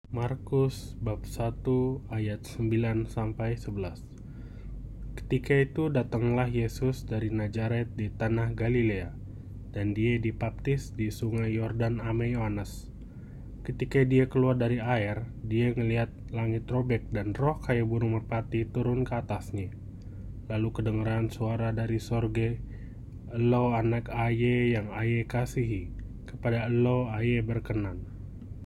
중부 베타위 방언으로 말하는 남성의 음성 샘플